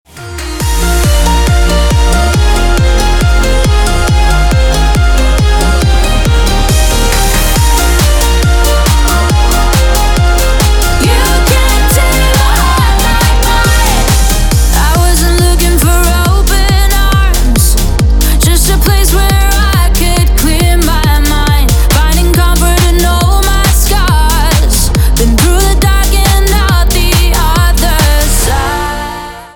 Категория: Громкие звонки и рингтоны